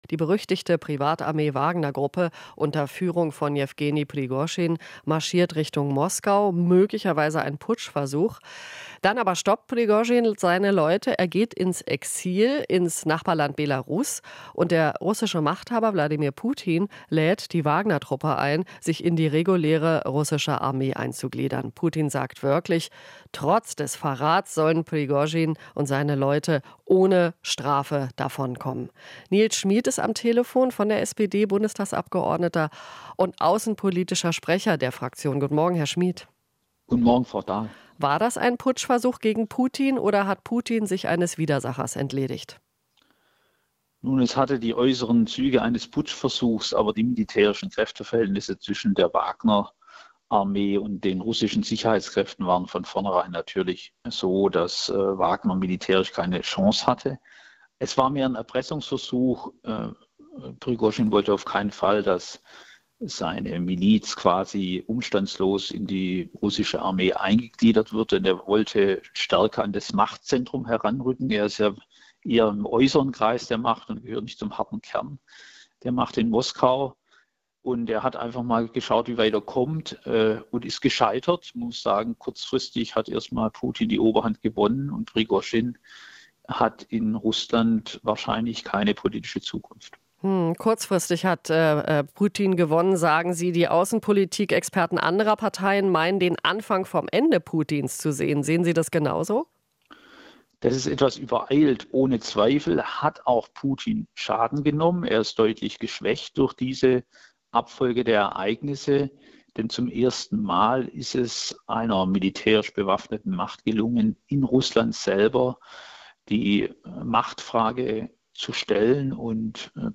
Interview - Schmid (SPD): Wagner-Aufstand hat Putin geschwächt